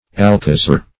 alkazar - definition of alkazar - synonyms, pronunciation, spelling from Free Dictionary Search Result for " alkazar" : The Collaborative International Dictionary of English v.0.48: Alkazar \Al*ka"zar\ See Alcazar .